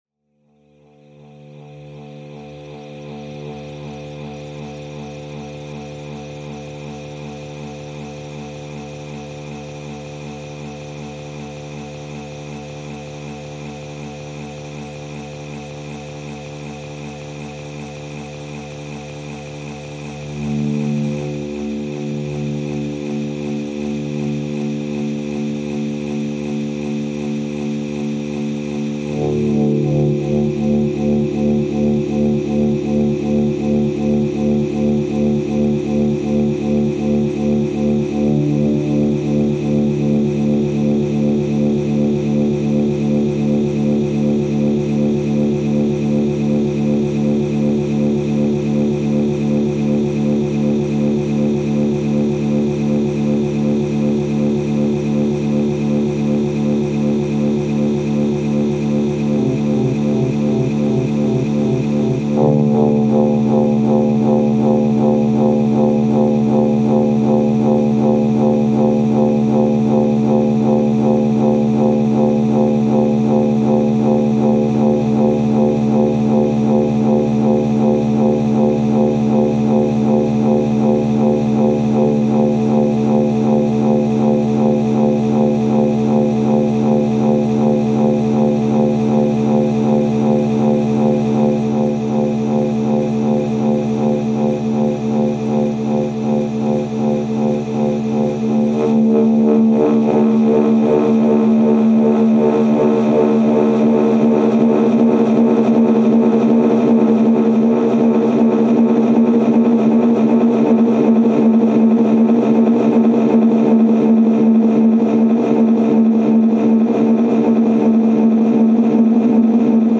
Nas casas de banho públicas de um Mercado Municipal é expectável a audição de sons.
Não será normal que a partir da casa de banho feminina uma eletrizante onda sonora irradie em espirais de sons distorcidos.